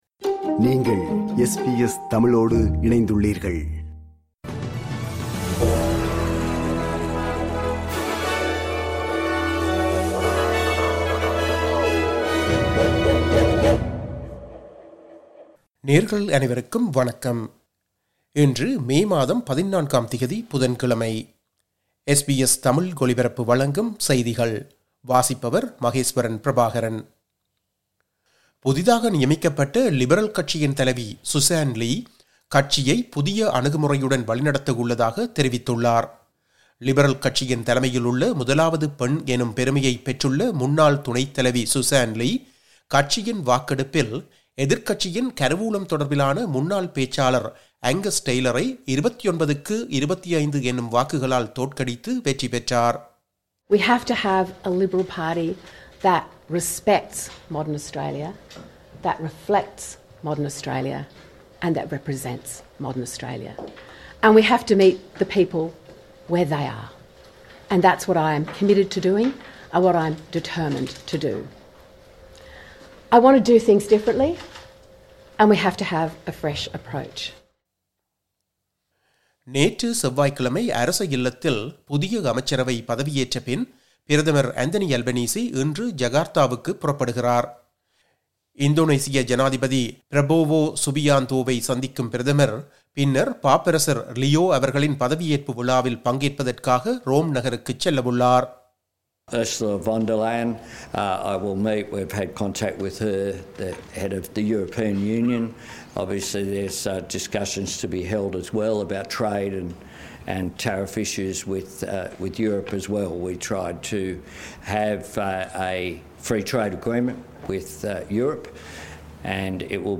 SBS தமிழ் ஒலிபரப்பின் இன்றைய (புதன்கிழமை 14/05/2025) செய்திகள்.